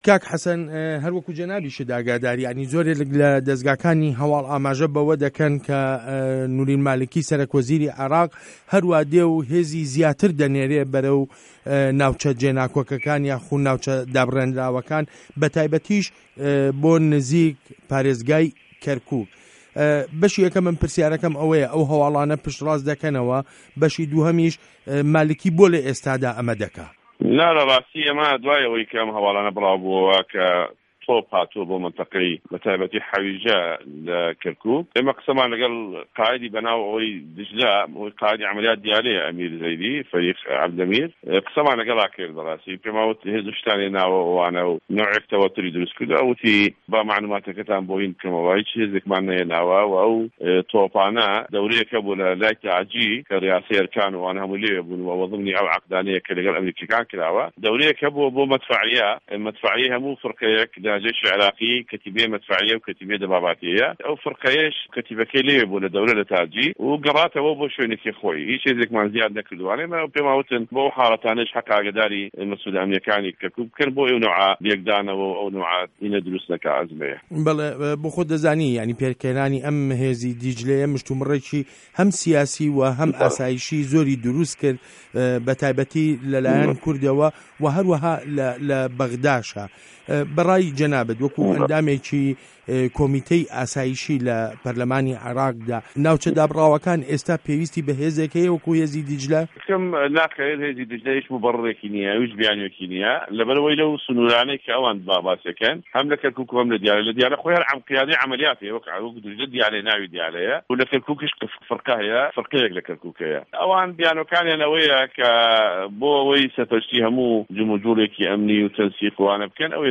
وتووێژ له‌گه‌ڵ حه‌سه‌ن جیهاد